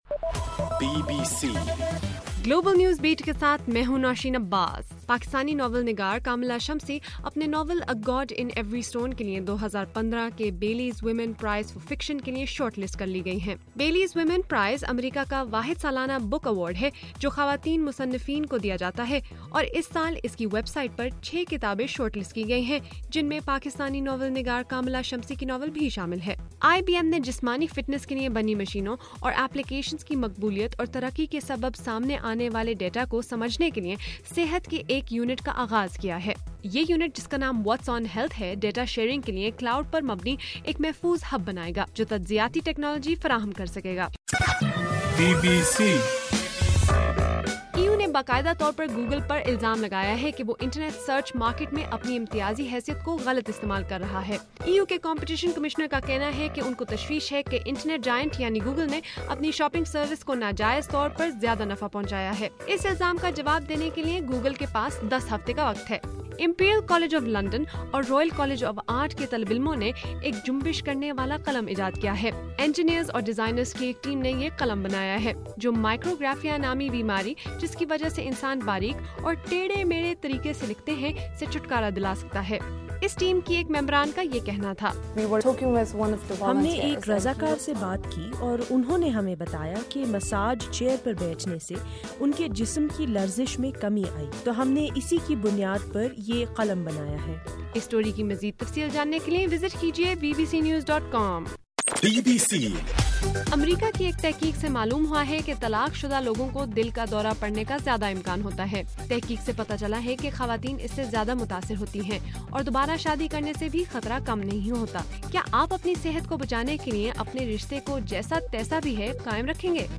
اپریل 15: رات 12 بجے کا گلوبل نیوز بیٹ بُلیٹن